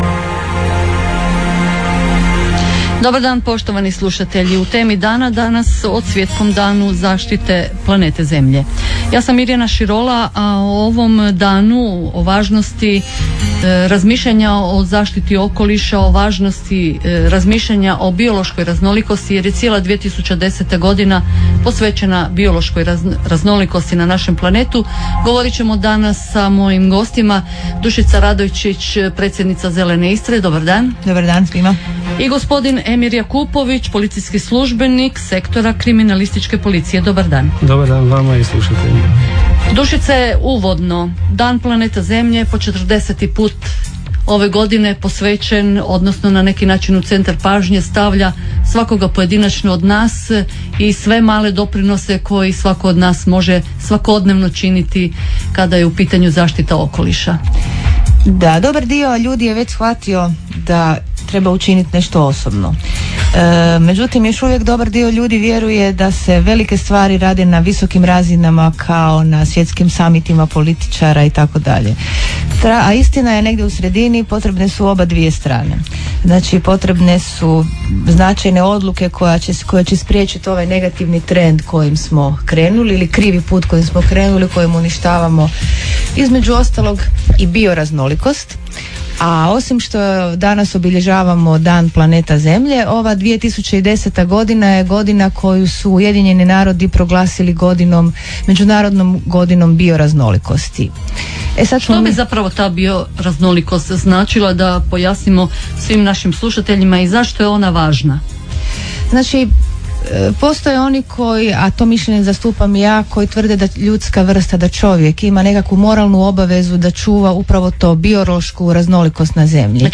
Fotografije Prilozi radiopula_stream_recording-2010-04-22-dan_planeta_zemlja.mp3 Preuzeto 1524 puta